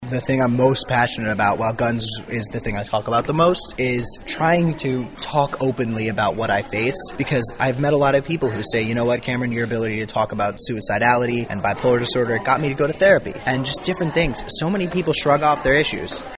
K-State was the latest destination for Kasky as he participated in a public question-and-answer presentation called “Tools for a Movement” at the K-State Student Union Tuesday.